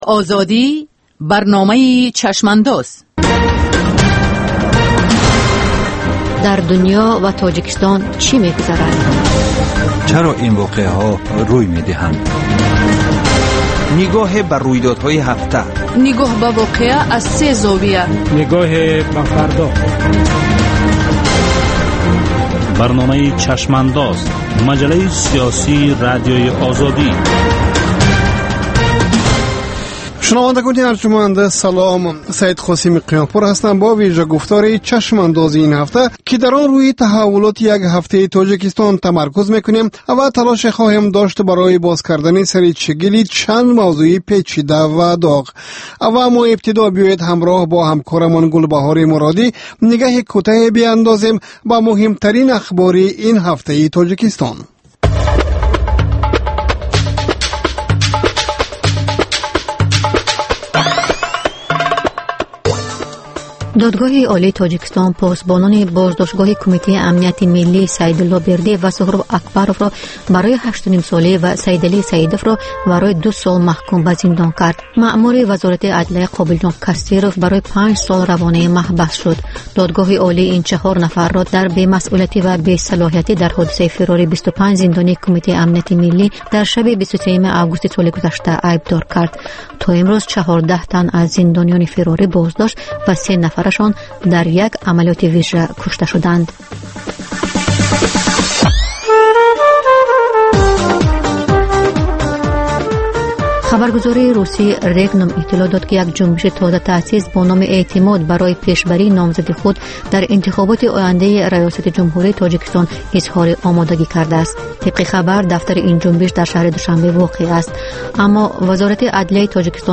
Нигоҳе ба таҳаввулоти сиёсии Тоҷикистон, минтақа ва ҷаҳон дар ҳафтае, ки гузашт. Гуфтугӯ бо сиёсатмадорон ва коршиносон.